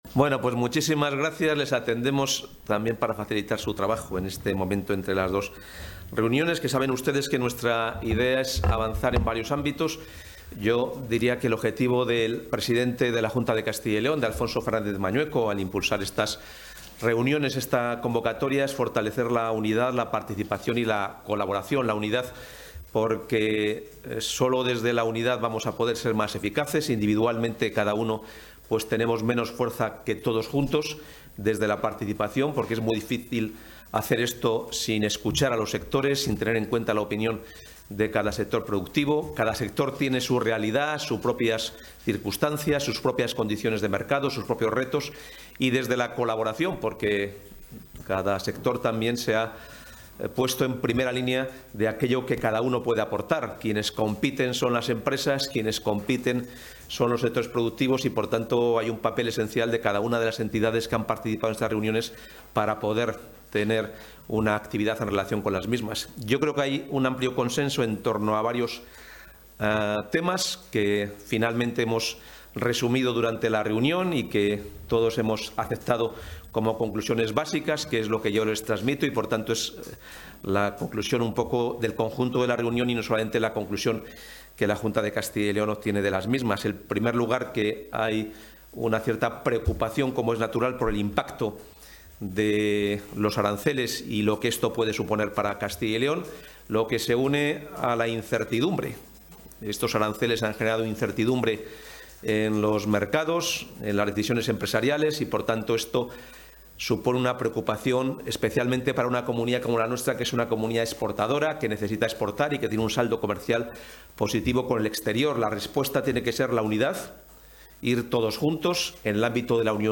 Intervención del portavoz de la Junta.